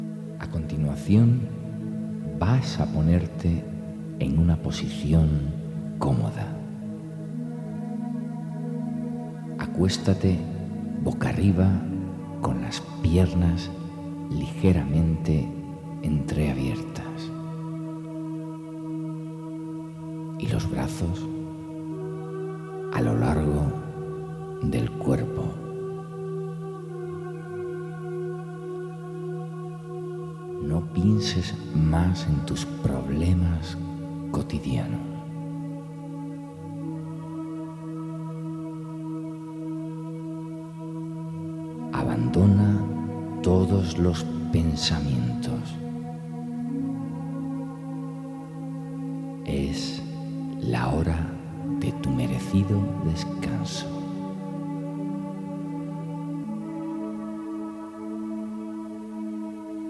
Si se usan con un reproductor de sonido, es conveniente oírlos usando auriculares, ya que existen ligeras diferencias entre la frecuencia que percibe un oído y el otro, para aumentar su eficacia y esto se pierde si se escuchan a través de altavoces.